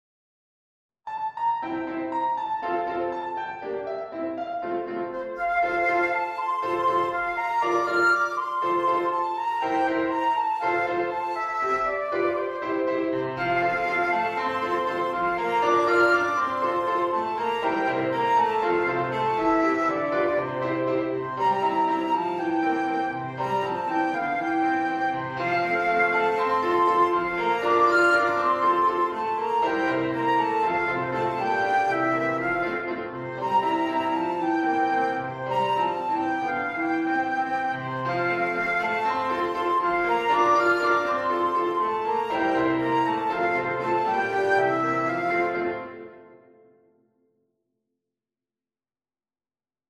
Antologia di 12 brani classici per Flauto e Pianoforte
Allegro giocoso